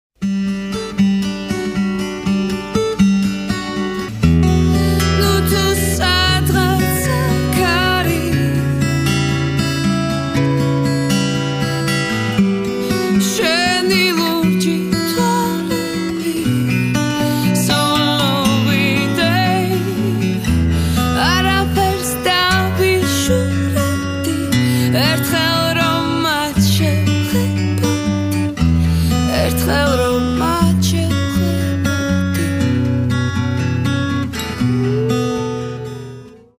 • Качество: 320, Stereo
гитара
женский вокал
спокойные